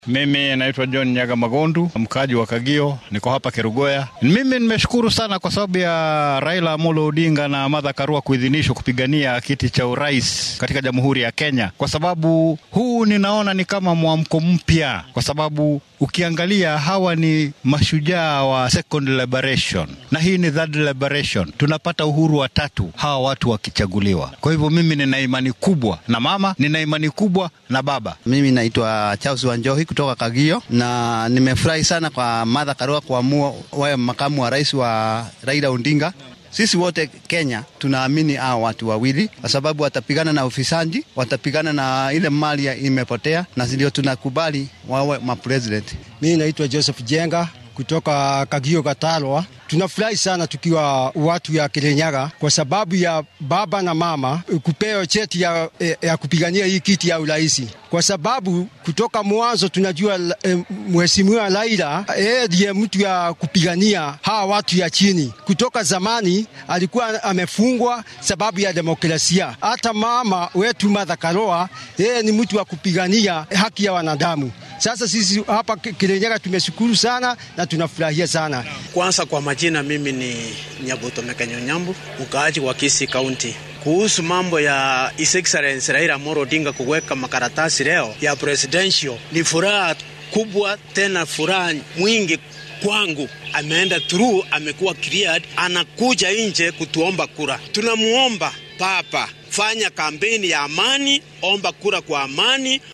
DHAGEYSO:Shacabka oo ka ra’yi dhiibtay ansaxinta Raila iyo Karua
Qaar ka mid ah dadweynaha ku nool ismaamullada Kirinyaga iyo Kisii ayaa muujiyay dareenkooda ku aaddan tallaabada maanta guddiga madaxa bannaan ee doorashooyinka iyo xuduudaha wadanka ee IEBC uu Raila Odinga ugu oggolaaday inuu ka mid noqdo musharraxiinta madaxweyne ee doorashada bisha Siddeedaad ee sanadkan. Dadweynaha ayaa arrintan soo dhaweeyay iyagoo siyaasiyiinta ugu baaqay inay ololahooda u sameystaan qaab nabad ah.